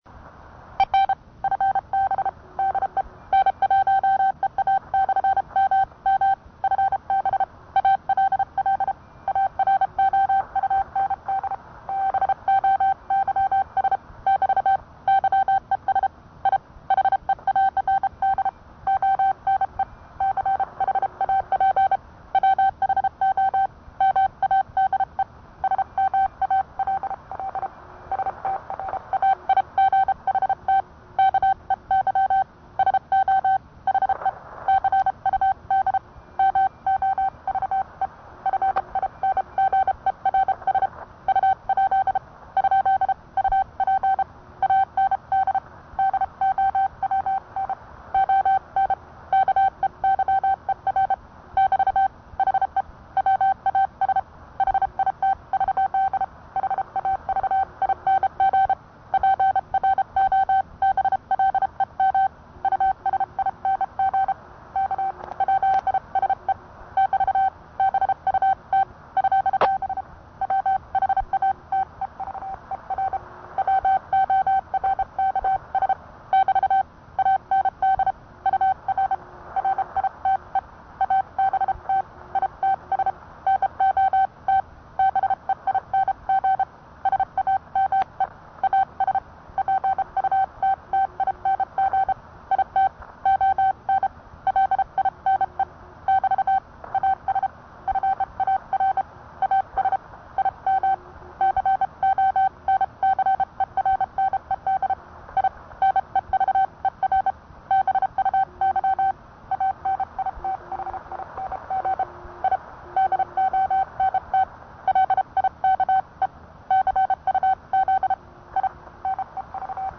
I've loaded some sound files for giving examples of different keys sending
Morse code.
The bug recording is about 25 years